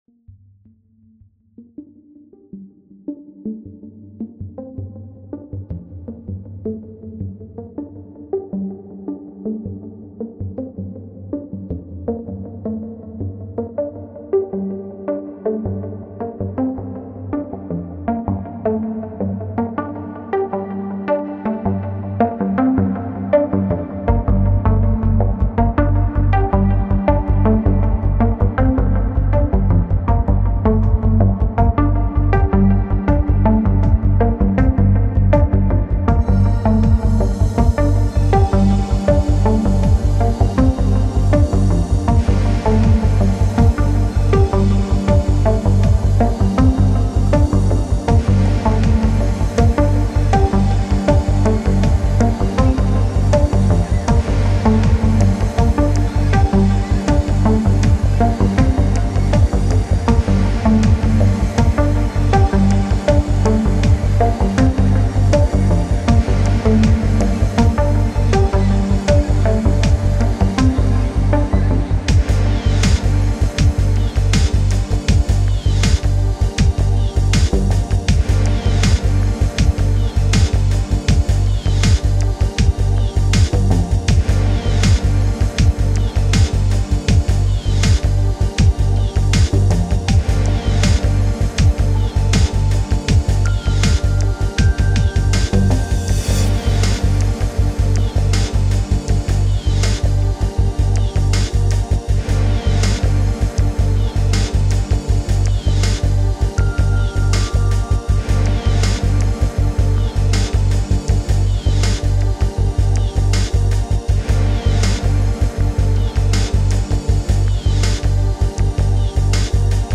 Hi, my new song, as always only free VST and only Madtracker 2.6 Wink
your tracks are always awesome mate, this one is no exception, in fact i think its my favourite of yours. bass is particularly good. cant believe its 26 minutes long Very Happy